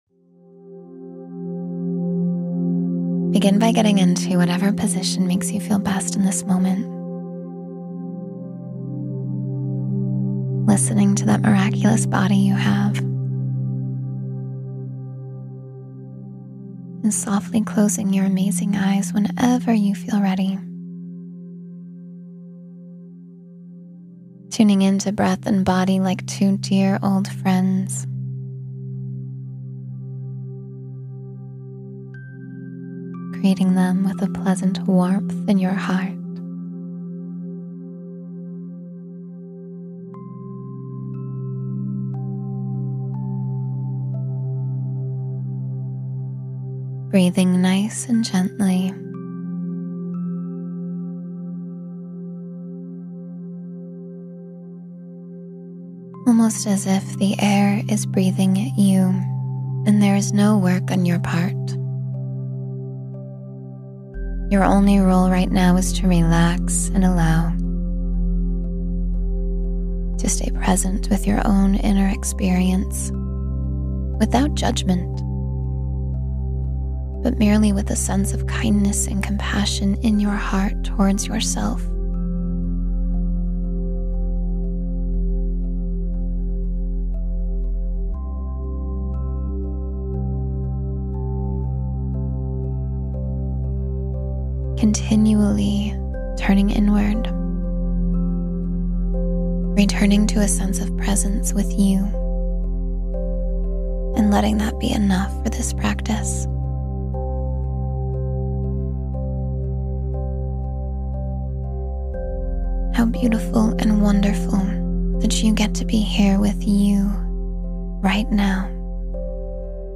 Your Wish Is Manifesting—Feel It Now — Meditation for Manifestation and Clarity